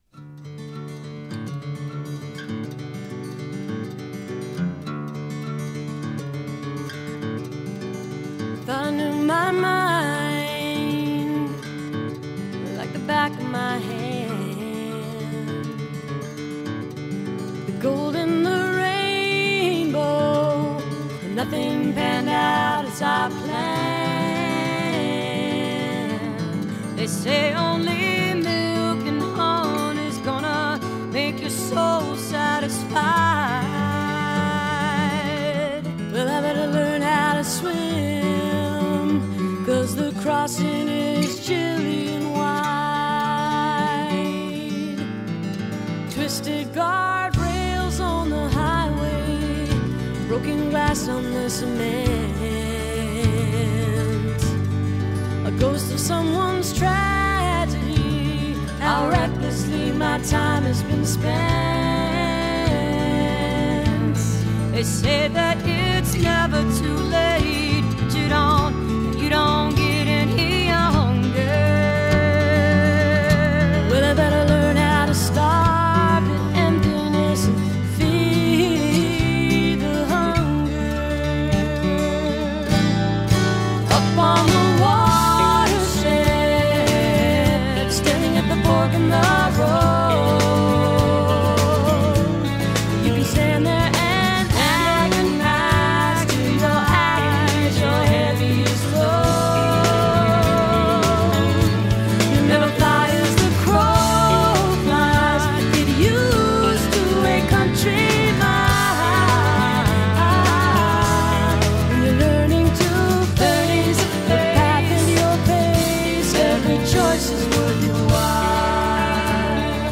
(web stream capture)